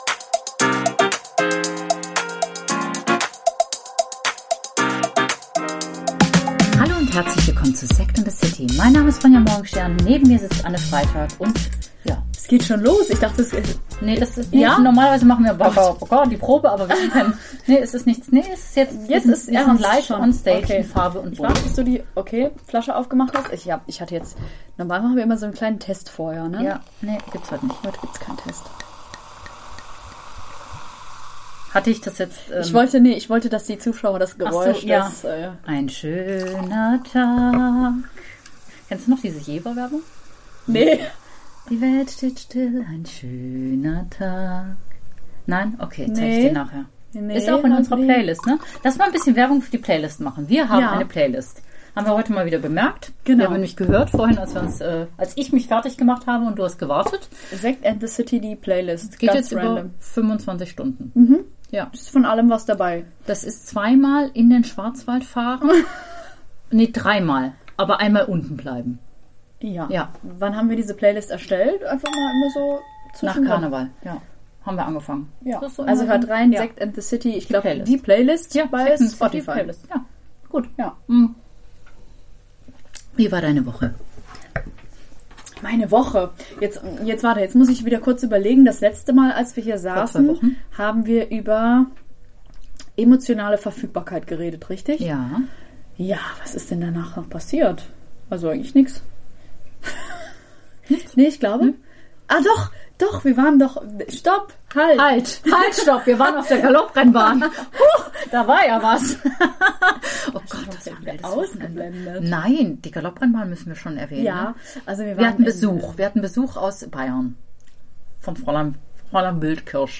Namen sind Schall und Rauch.. oder etwa nicht? In dieser Folge sprechen die Ladies über ihre Namen, Spitznamen, welche Namen ihnen übel aufstossen und was man bei der Wahl des Kindesnamen berücksichtigen sollte...